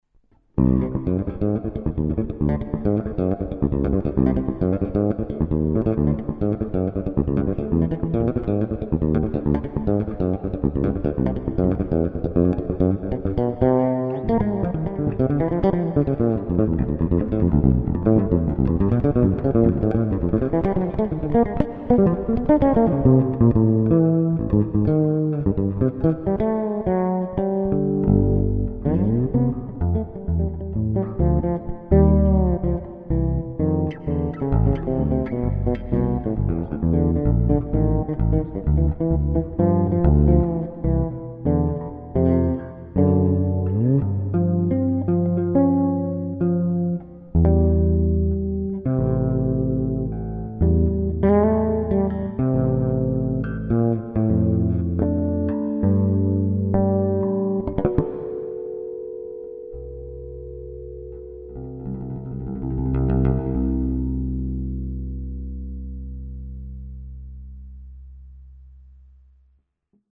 • Re-radiused and HG Thor Epoxied Fender '62 Jaco Reissue